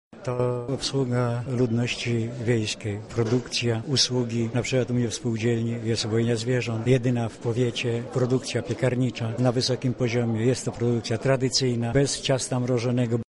Tak uważają organizatorzy Forum Spółdzielców którzy spotkali się dzisiaj na Katolickim Uniwersytecie Lubelskim.